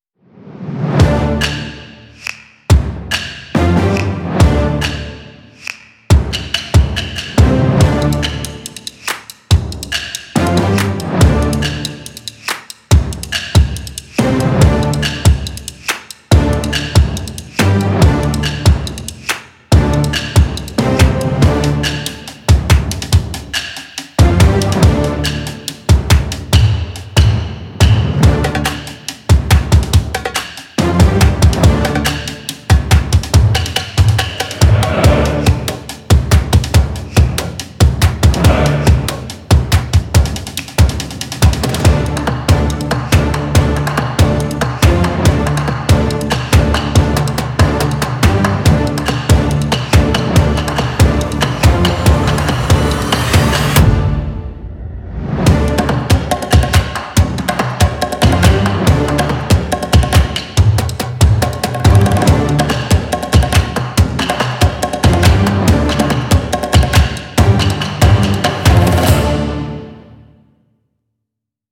Genre: trailer, filmscore, tribal, production.